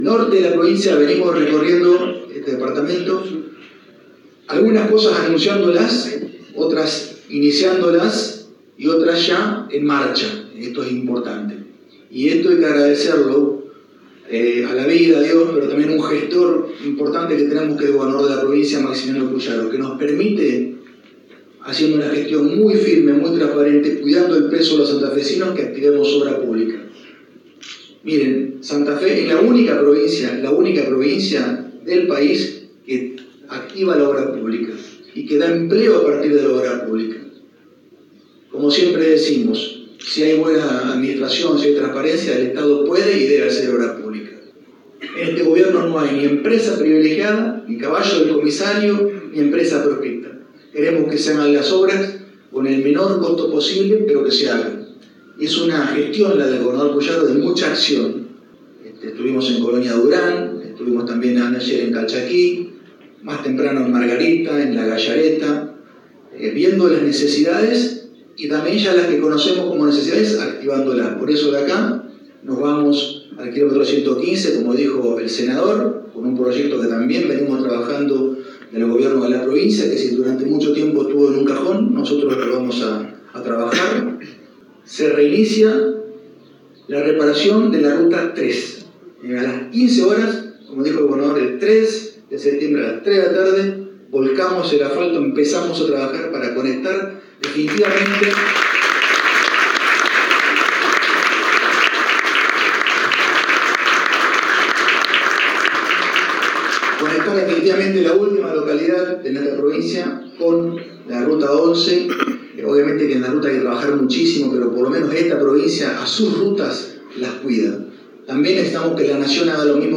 Entre otras llevará agua potable al Paraje 115 de la localidad de Garabato y hay otras en marcha y a realizarse. Palabras del Ministro de Obras Públicas, Lisando Enrico.
Lisandro Enrico – Ministro de Obras Públicas del gobierno de Santa Fe